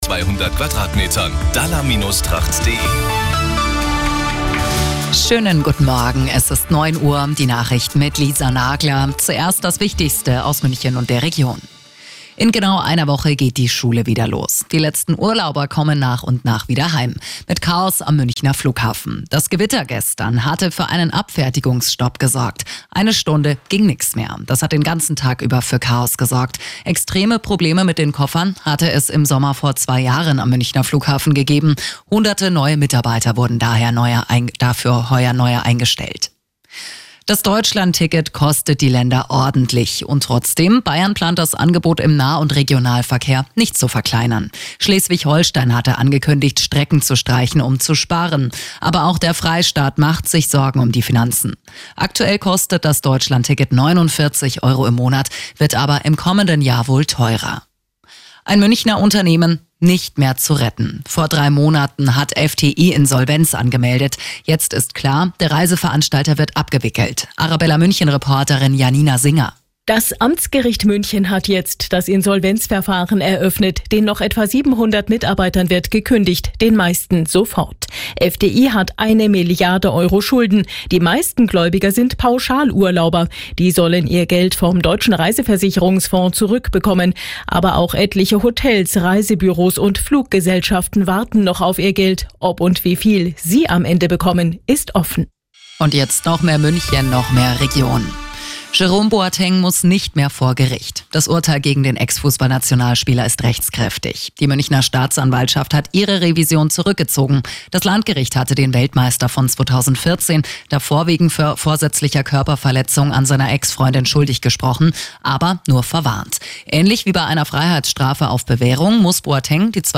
Die Radio Arabella Nachrichten von 11 Uhr - 03.09.2024